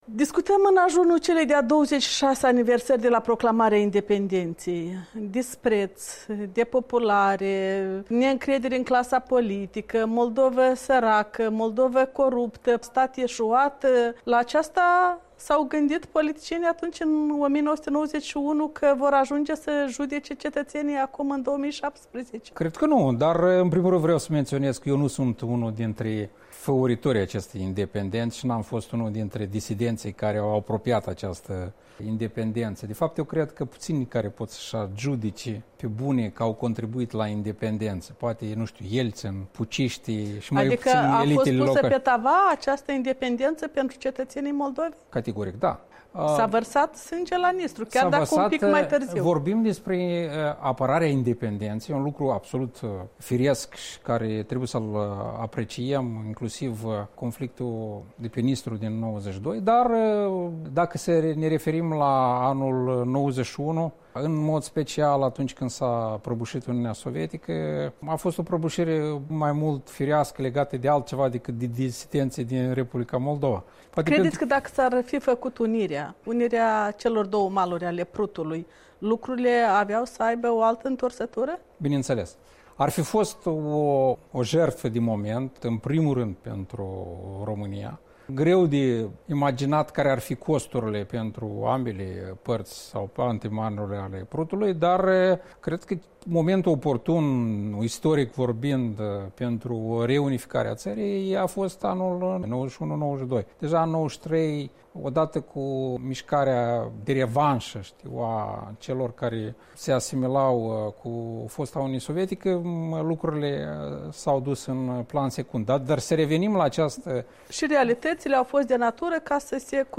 Un interviu cu Ion Sturza